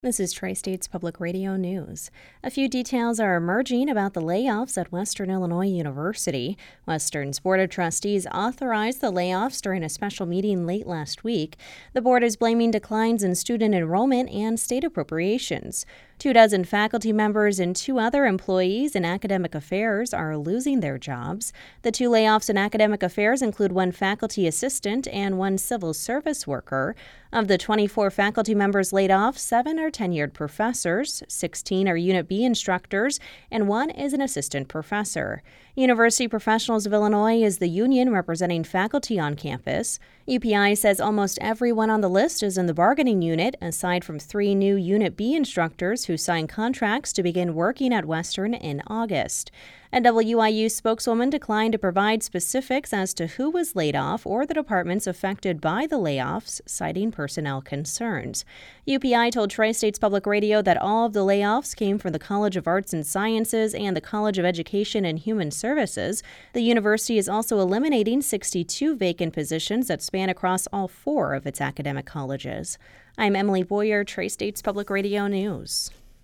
the radio news report